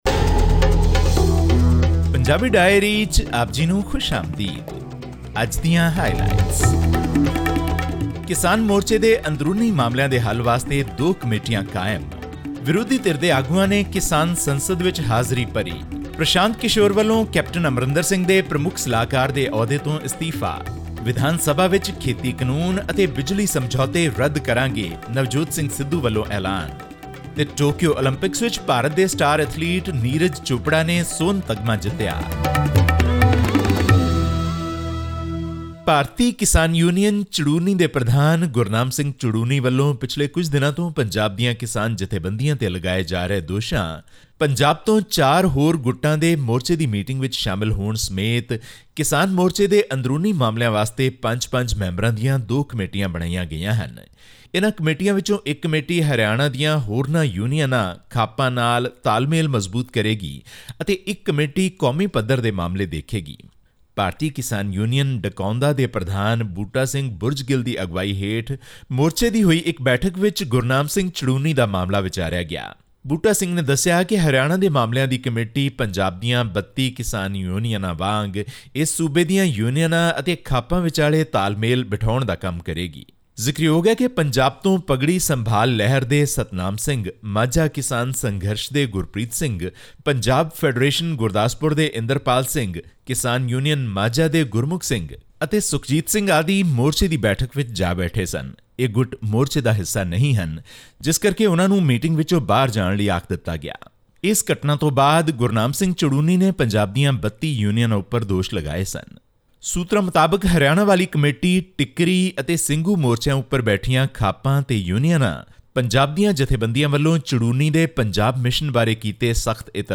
Several opposition party leaders joined the ongoing farmers' protest at Jantar Mantar in New Delhi on 6 August to express solidarity with farmers protesting against the government's three contentious farm laws. This and more in our weekly news bulletin from Punjab.